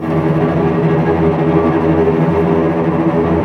Index of /90_sSampleCDs/Roland LCDP08 Symphony Orchestra/STR_Vcs Bow FX/STR_Vcs Trem wh%